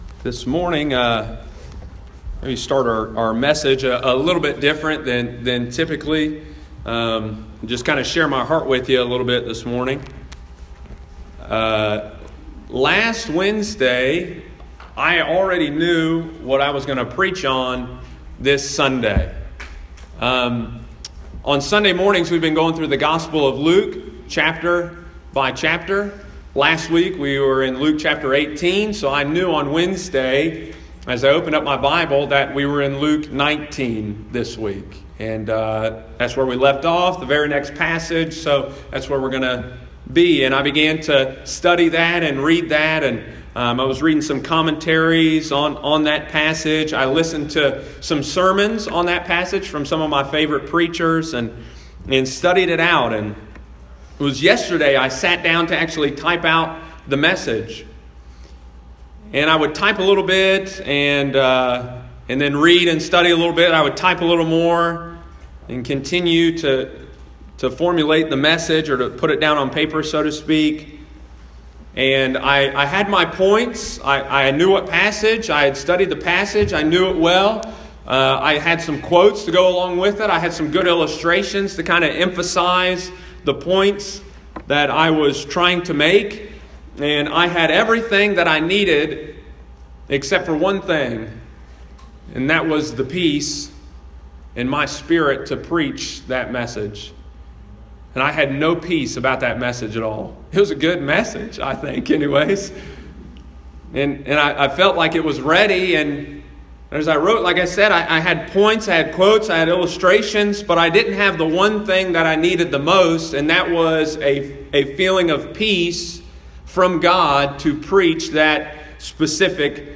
Sunday morning, September 16, 2018.